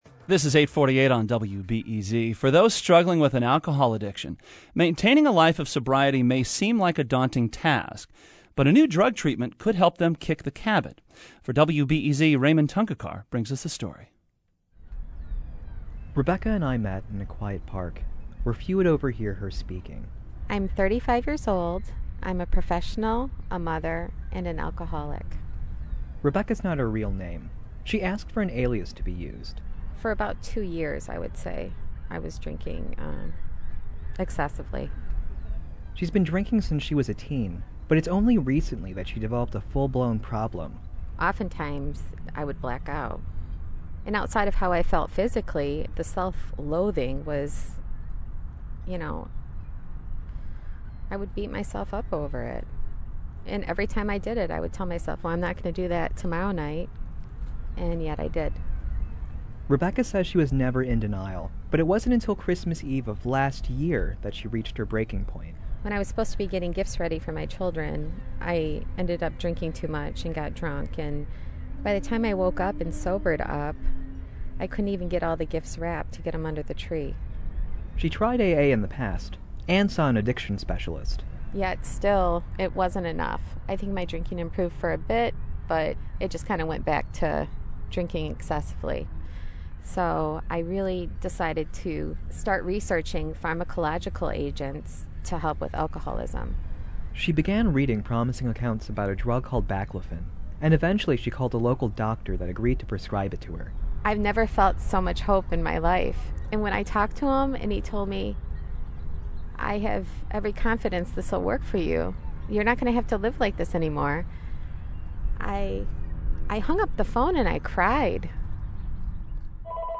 L’interview :